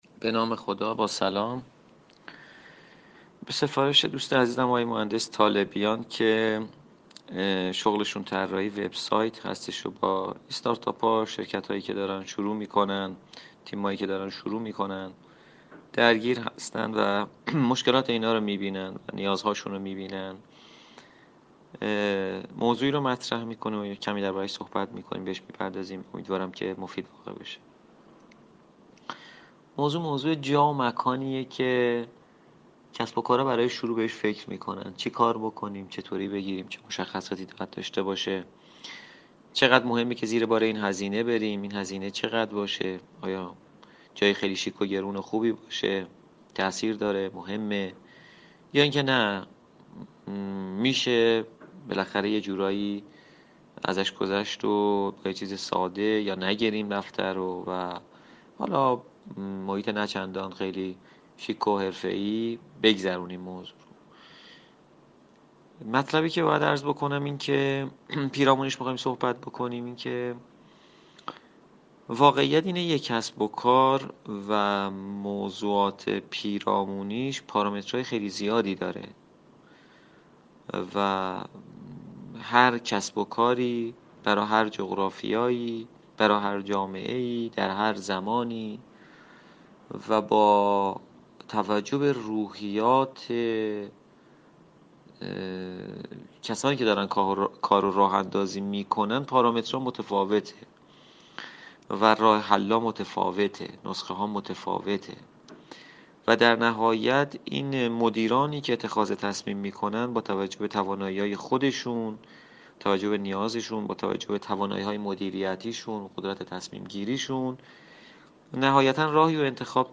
بیان شیوا و صمیمی اش